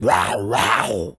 tas_devil_cartoon_03.wav